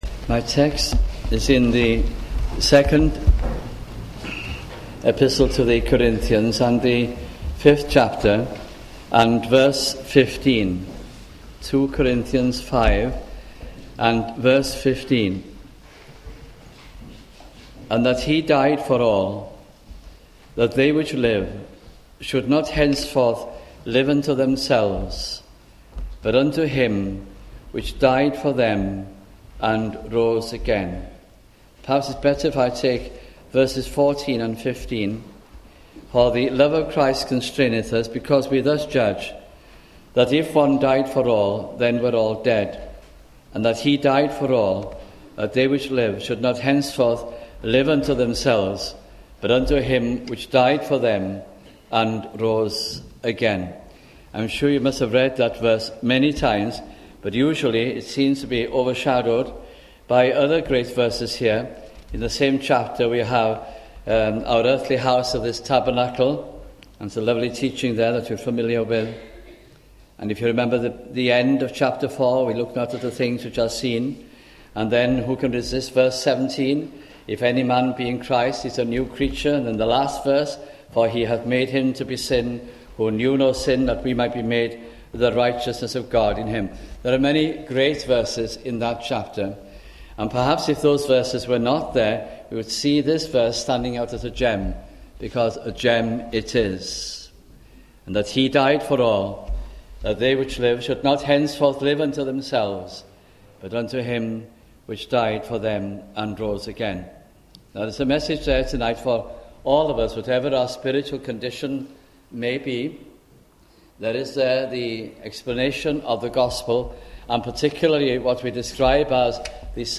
» 2 Corinthians Gospel Sermons